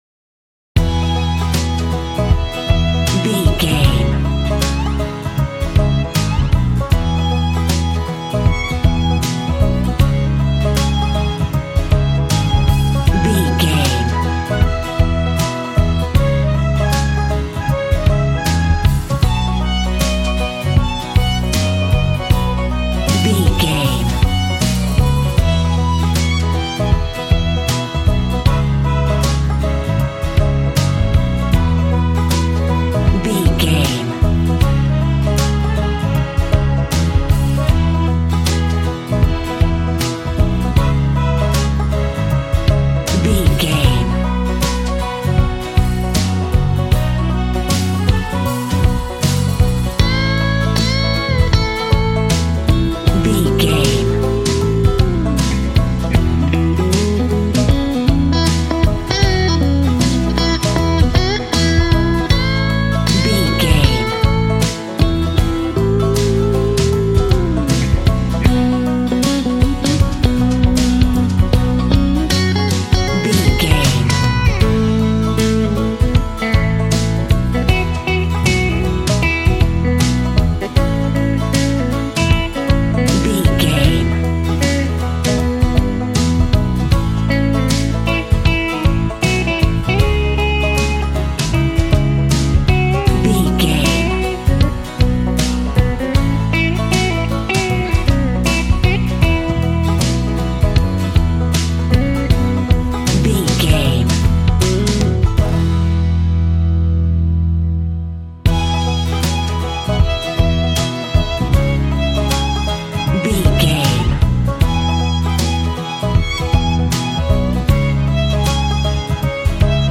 Country music with the violin playing the melody.
Ionian/Major
Fast
fun
bouncy
double bass
drums
acoustic guitar